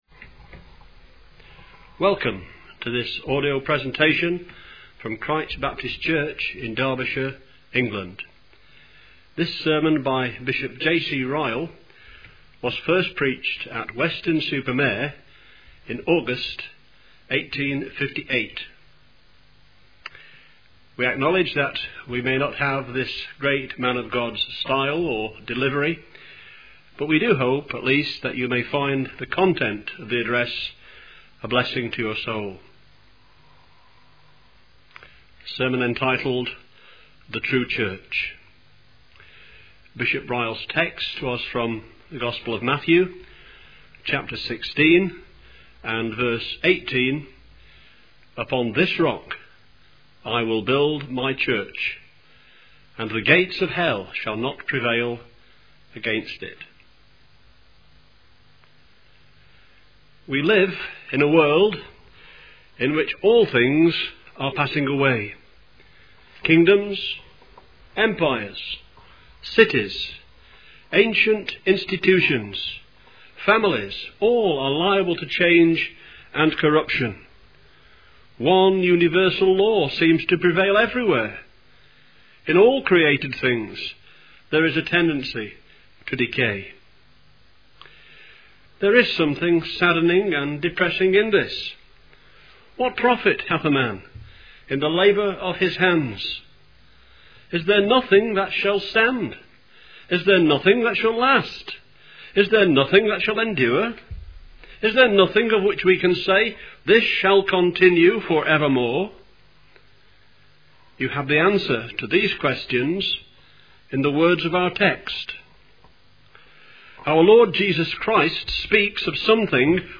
The True Church (Reading) by J.C. Ryle | SermonIndex